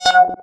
rolldown.wav